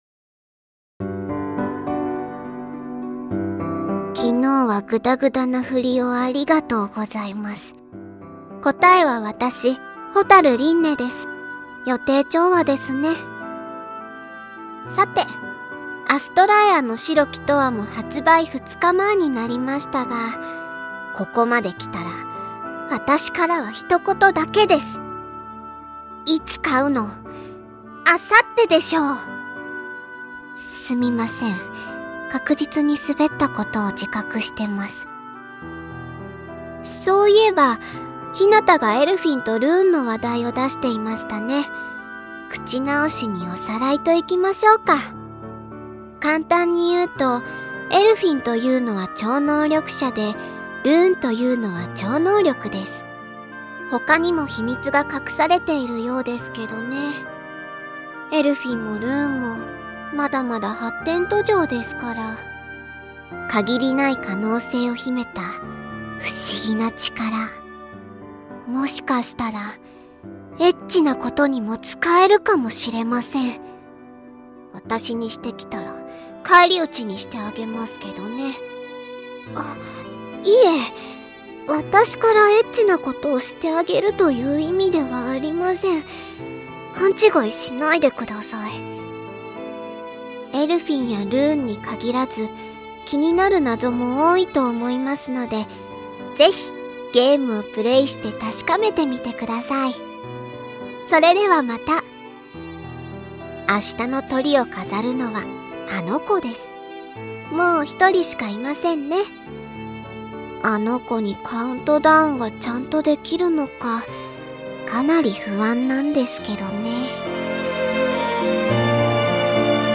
『アストラエアの白き永遠』 発売2日前カウントダウンボイス(りんね)を公開